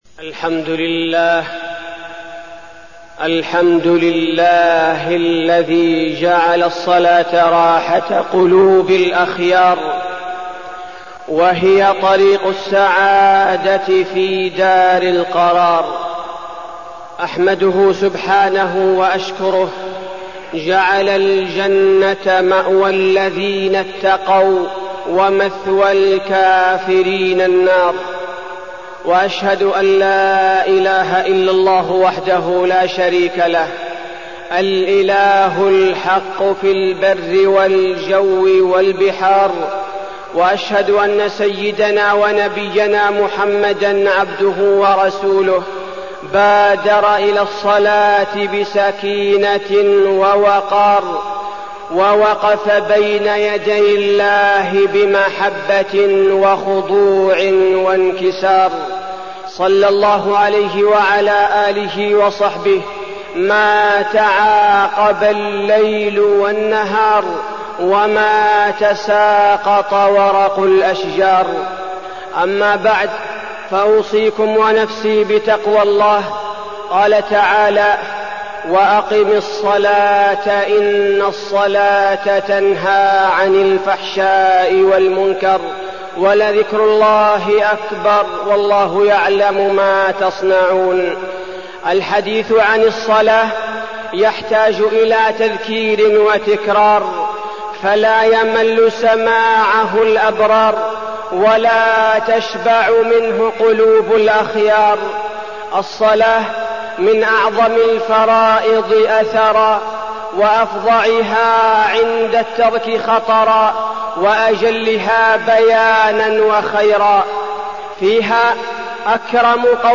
تاريخ النشر ٦ جمادى الأولى ١٤١٩ هـ المكان: المسجد النبوي الشيخ: فضيلة الشيخ عبدالباري الثبيتي فضيلة الشيخ عبدالباري الثبيتي الصلاة